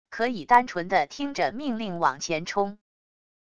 可以单纯的听着命令往前冲wav音频生成系统WAV Audio Player